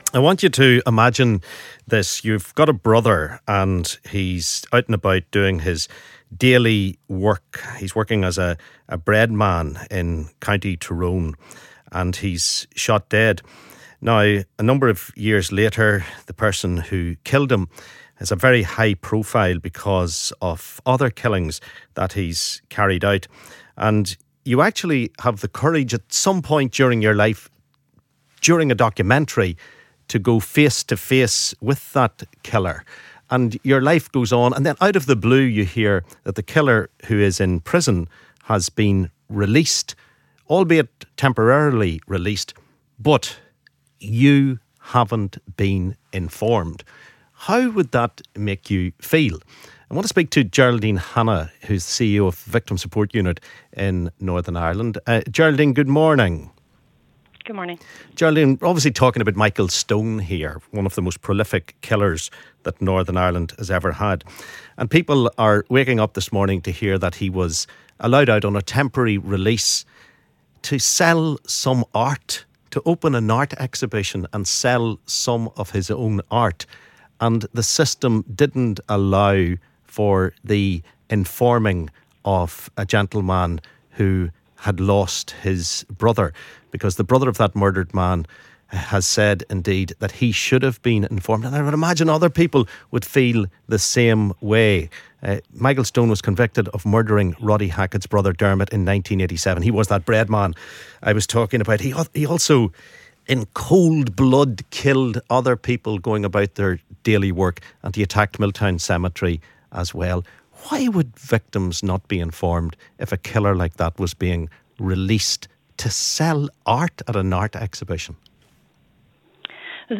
LISTEN ¦ Loyalist killer Michael Stone opens art exhibition while on prison release - callers react